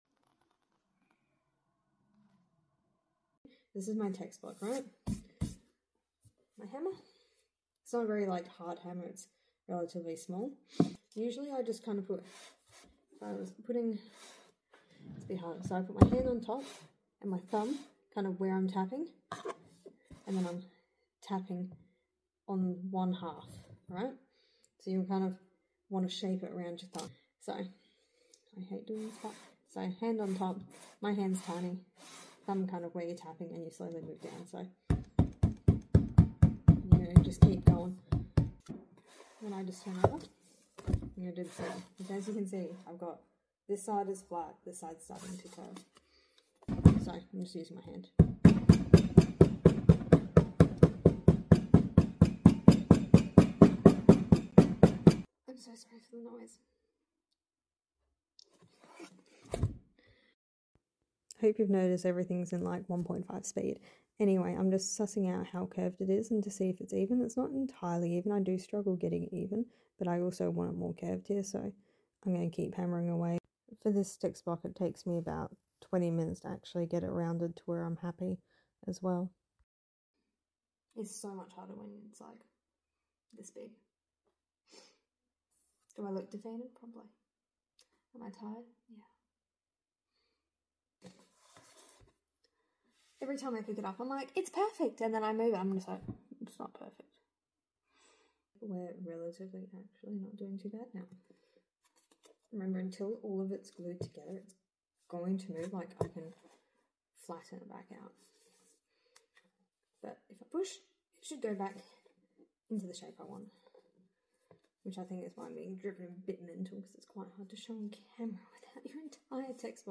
Rounded Spine Tutorial Part 2: Rounding welcome to me being defeated for 4 minutes straight. I just use a small hammer. Please note I did reheat the glue a tiny bit but it needed more heating and it took longer for me to hammer than usual because of it.